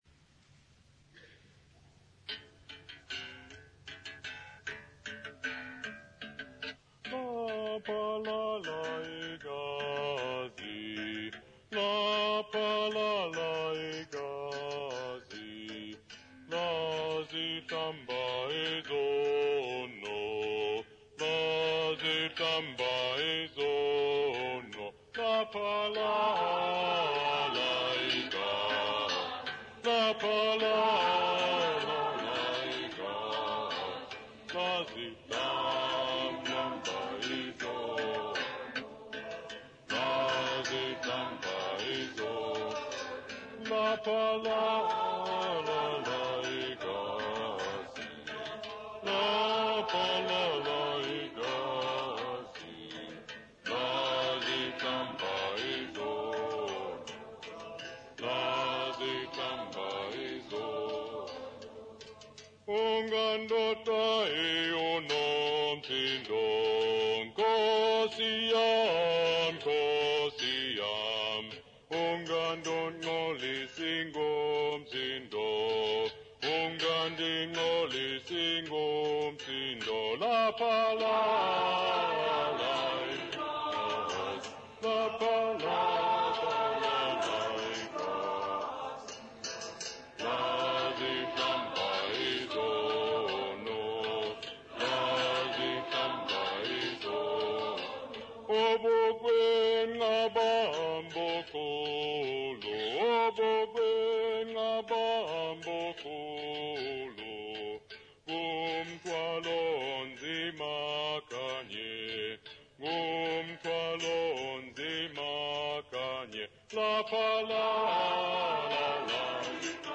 >St Gabriel's church choir Gugulethu
Sacred music South Africa
Folk music South Africa
Stringed instrument music South Africa
Africa South Africa Gugulethu, Cape Town sa
field recordings
Church hymn with Uhadi bow accompaniment.